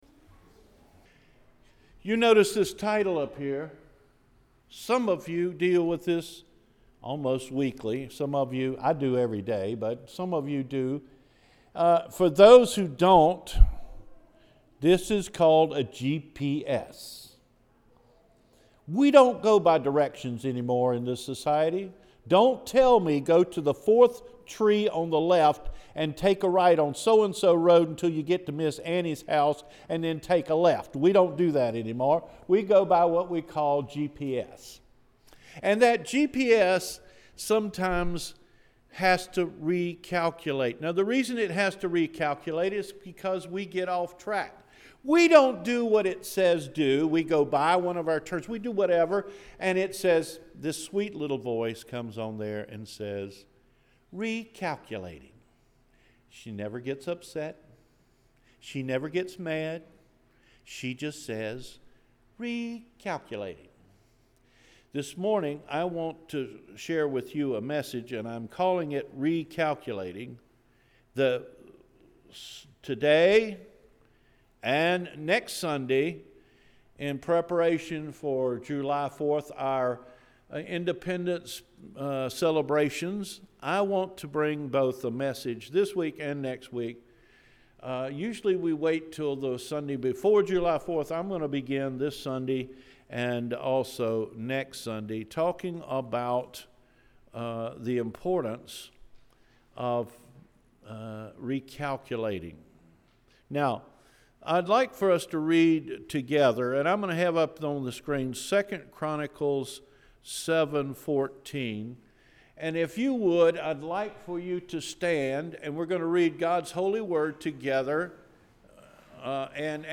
Recalculating – June 25 2017 Sermon